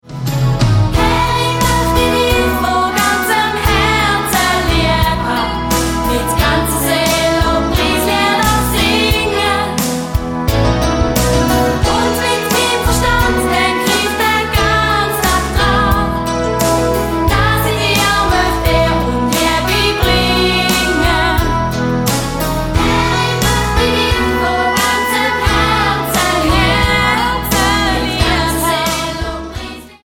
die vielen kurzen, eingängigen Refrains